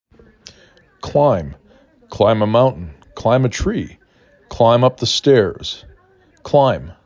5 Letters, 1 Syllable
k l I m